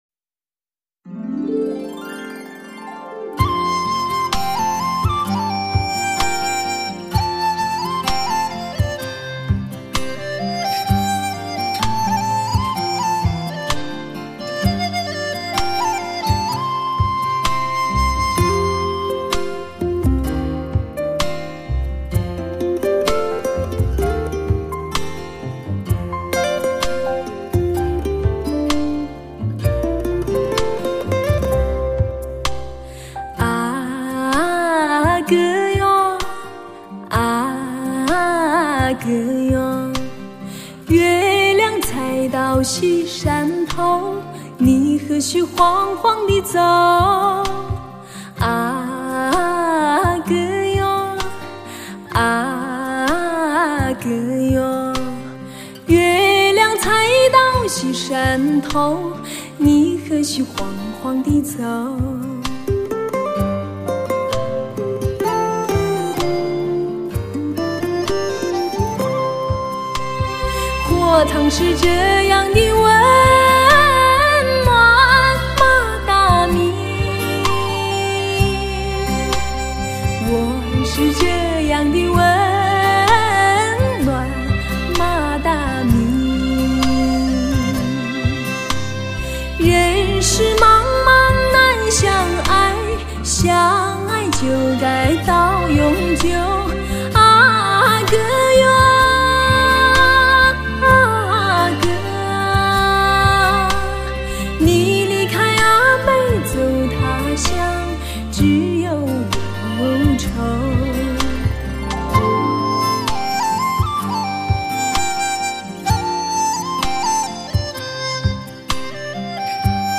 类型: 天籁人声